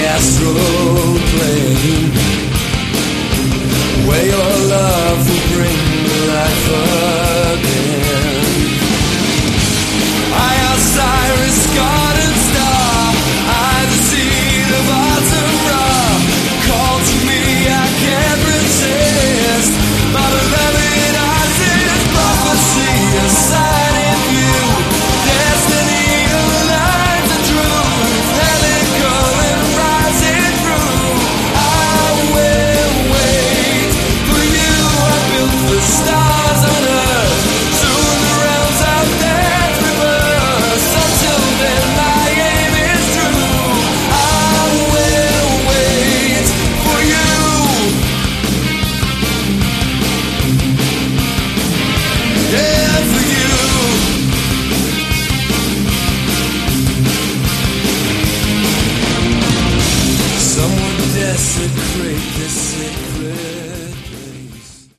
Category: AOR
lead and backing vocals
electric and acoustic guitars
drums, percussion
keyboards